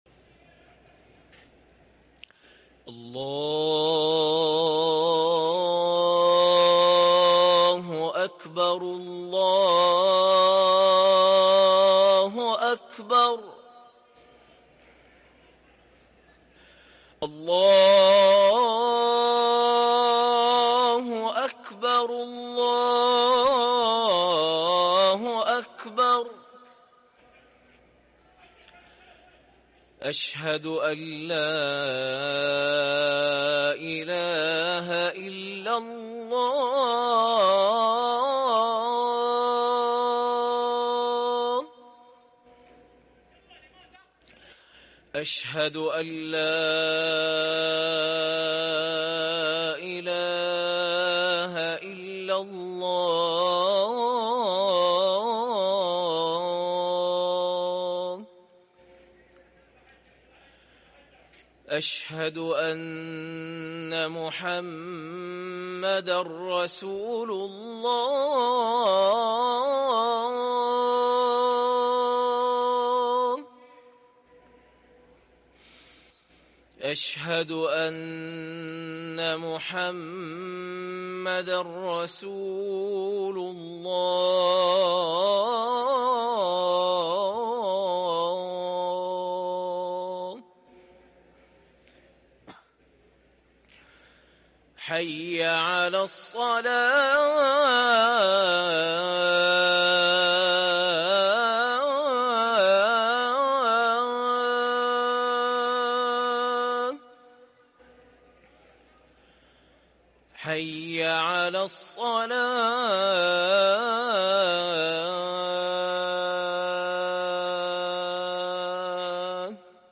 أذان الجمعة الأول